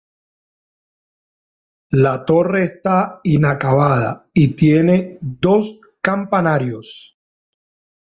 Pronúnciase como (IPA)
/ˈtore/